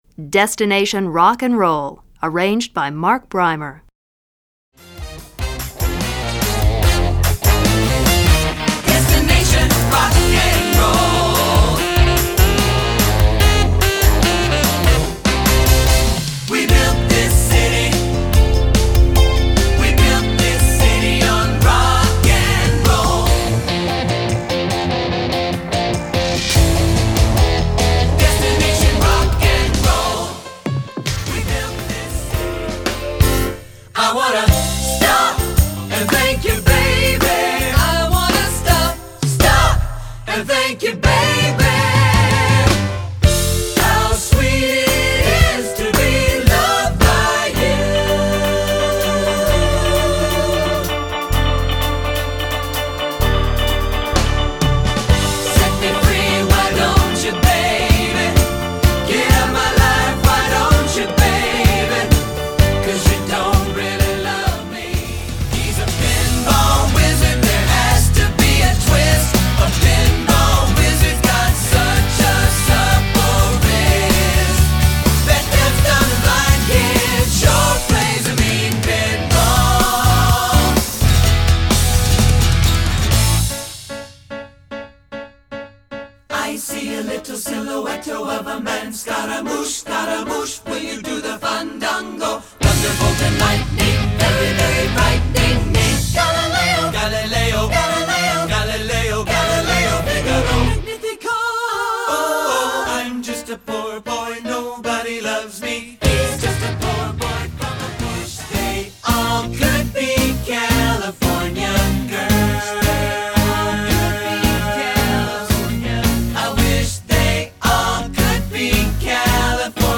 a hot accompaniment track